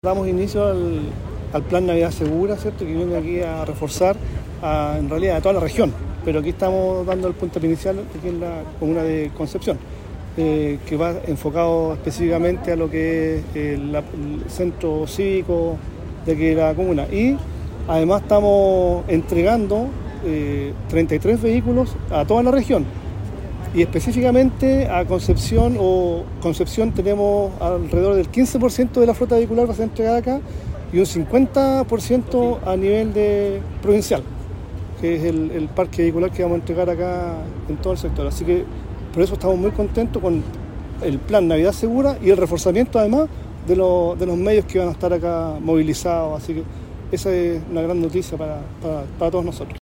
En una ceremonia efectuada en la Plaza Independencia de Concepción, autoridades regionales hicieron entrega formal de 33 nuevos vehículos policiales que serán distribuidos en distintas comunas de la Región, y destacaron que estos recursos logísticos reforzarán la base de la labor policial, los patrullajes, y la operatividad general de Carabineros en el territorio.